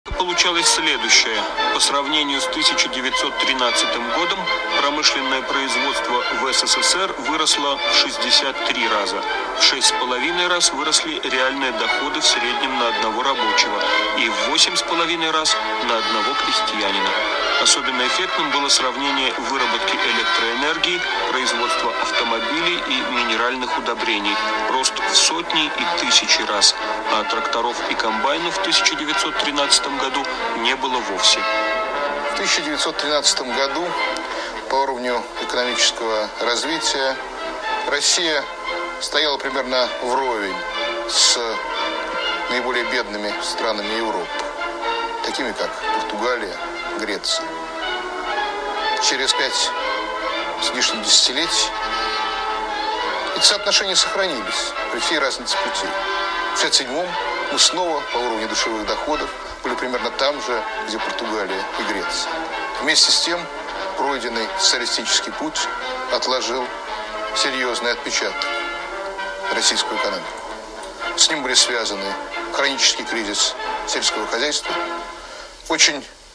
Возможно, и эта аранжировка песни...